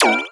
sci-fi_weapon_laser_small_fun_05.wav